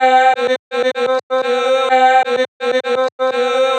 • chopped vocals 109-127 female 1 (12) - Cm - 127.wav